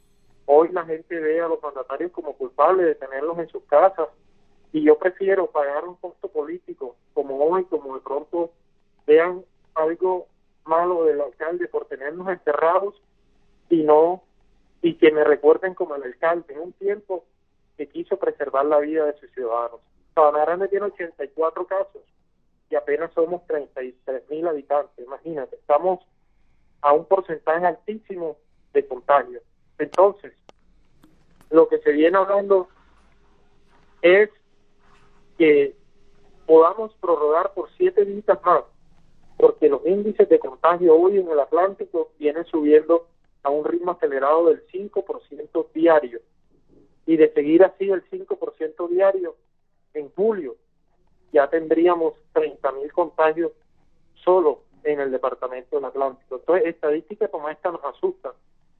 VOZ-ALCALDE-SABANAGRANDE-CONTAGIOS-COVID.mp3